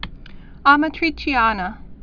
mə-trē-chēnə, ämä-trē-chyänä)